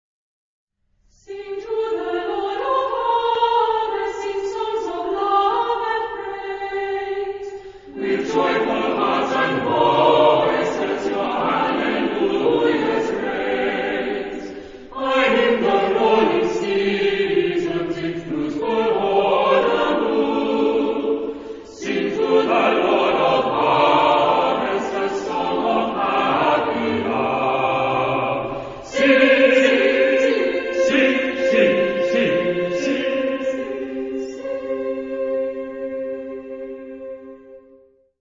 Genre-Style-Form: Christmas song
Mood of the piece: joyous
Type of Choir: SATB  (4 mixed voices )
Instrumentation: Piano  (1 instrumental part(s))
Tonality: C tonal center